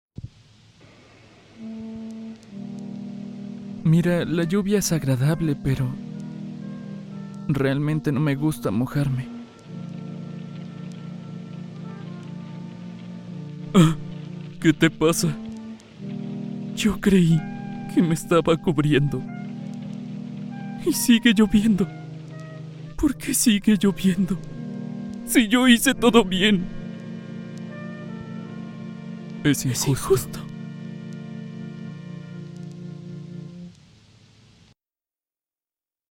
Práctica de doblaje